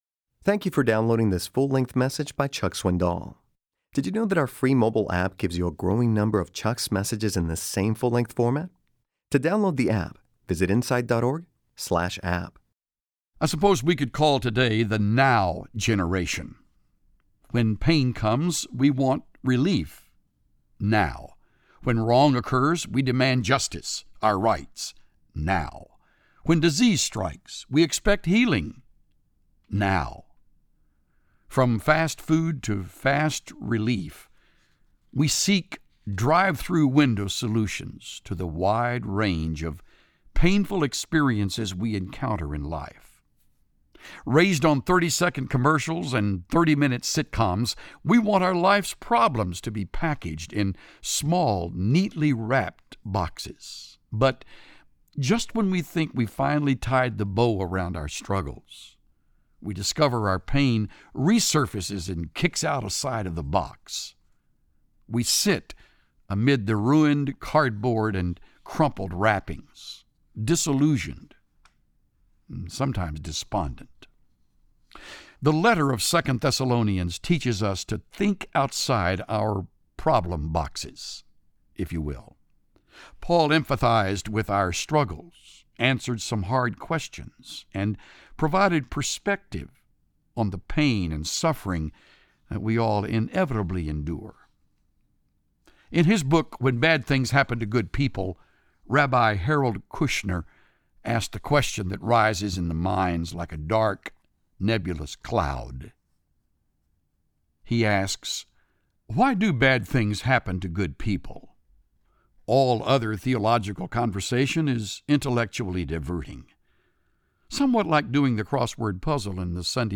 Pastor Chuck Swindoll teaches us not to fear the dark storm but to trust God’s grace.